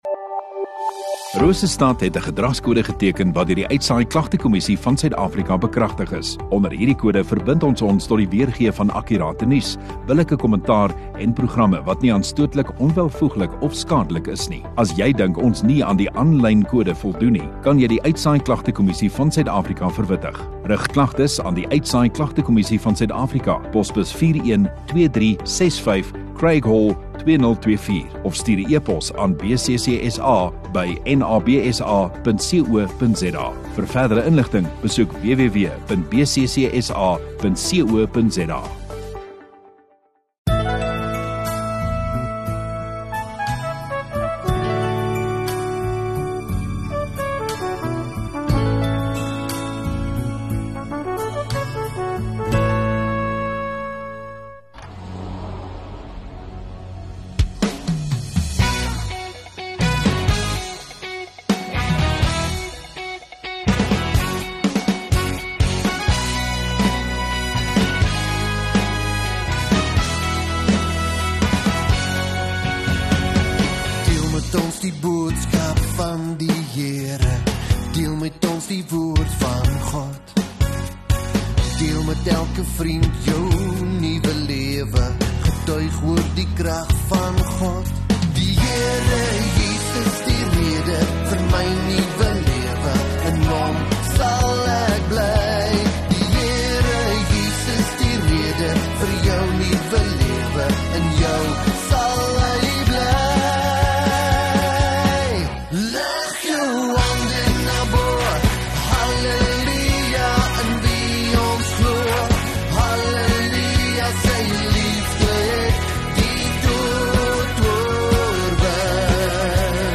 25 Oct Woensdag Oggenddiens